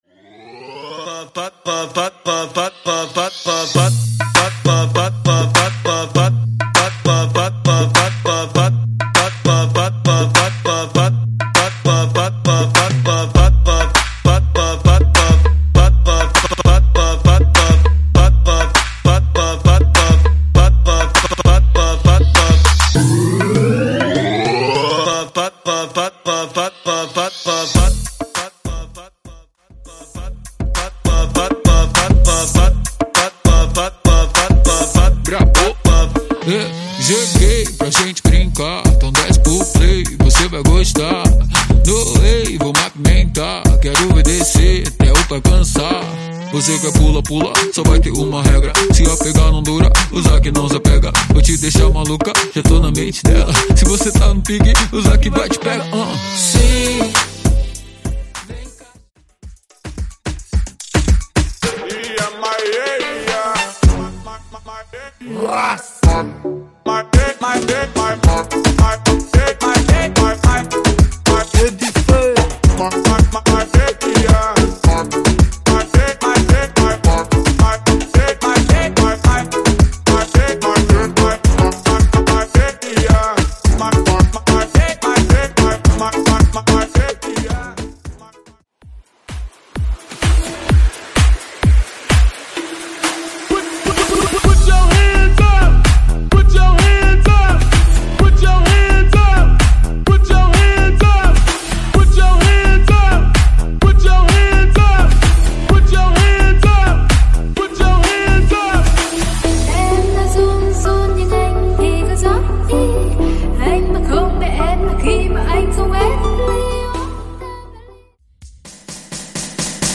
Genres: DRUM AND BASS , TOP40